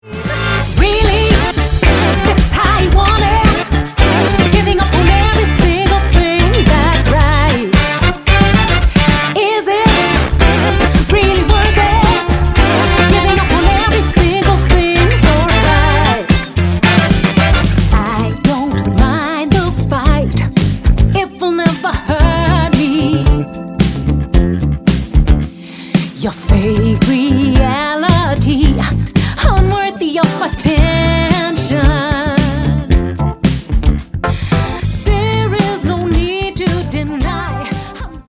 • Funk
• Pop
Alt er håndspillet og rammer både krop, hoved og sjæl.